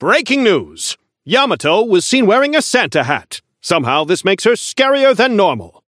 Newscaster voice line - Breaking news: Yamato was seen wearing a Santa hat!
Newscaster_seasonal_yamato_unlock_01.mp3